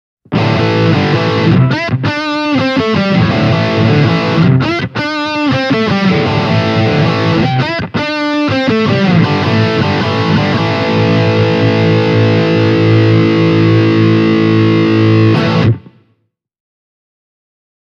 Ääniesimerkit on äänitetty Zoom H1 -tallentimilla.
Myös THR10X:n tapauksessa käytin LP-tyylistä kitaraa:
Yamaha THR10X – Brown II + Hall